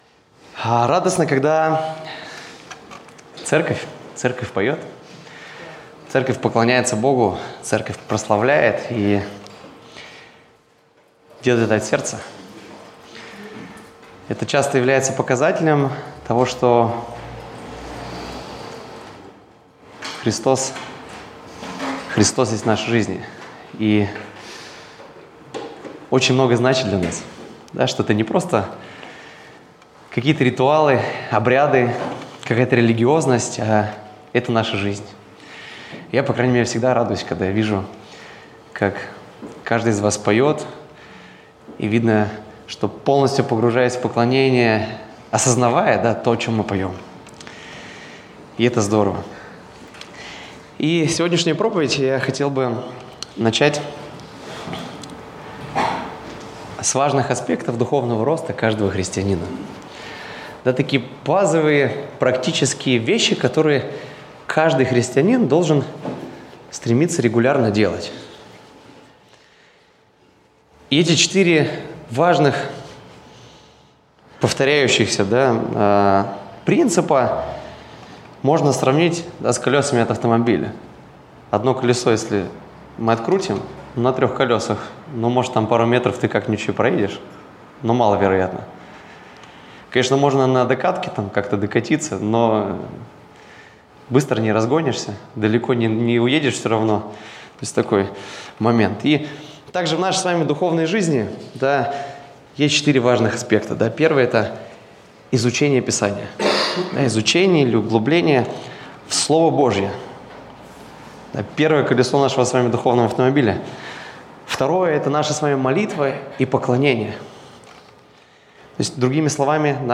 Воскресная проповедь - 2025-03-09 - Сайт церкви Преображение